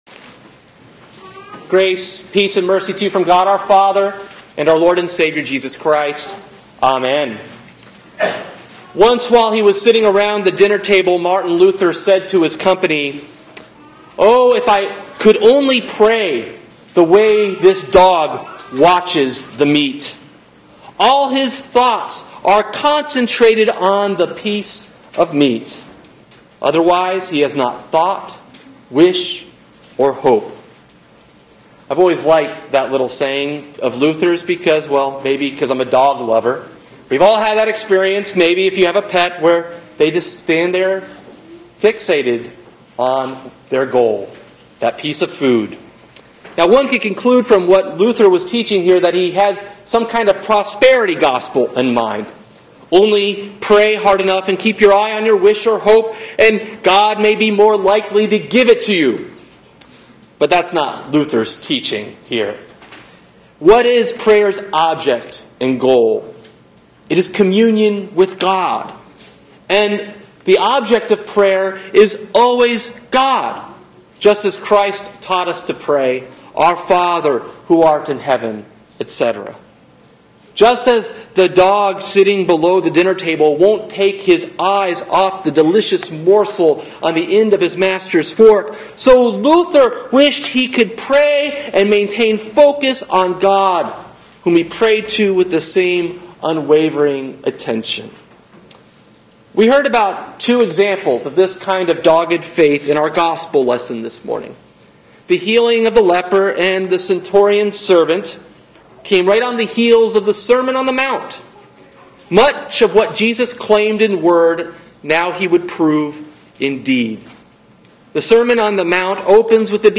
Sermon: Epiphany 3 Matthew 8:1-13